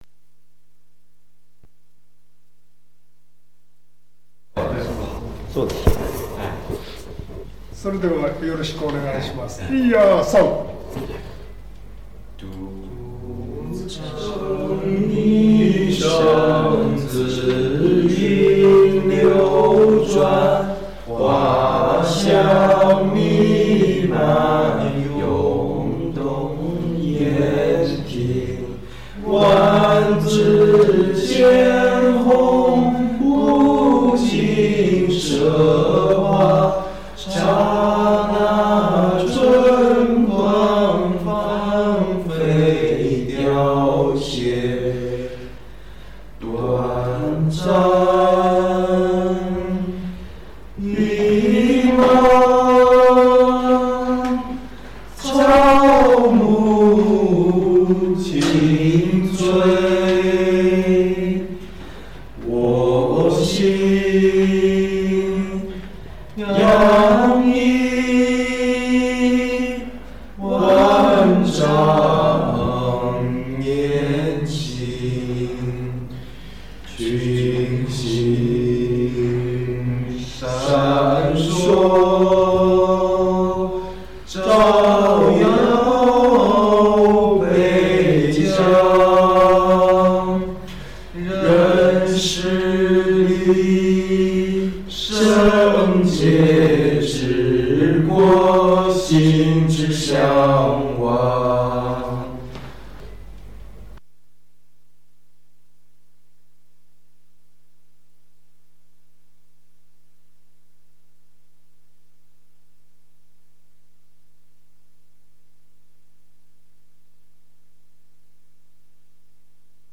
合唱；（１番）
合唱者；留学生　　（録音：2012年）